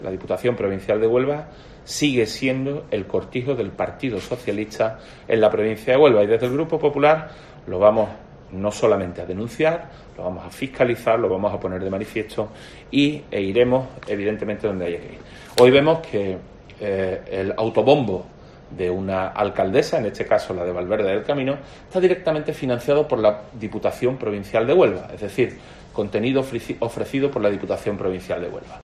AUDIO: Juan Carlos Duarte, portavoz del PP en Diputación de Huelva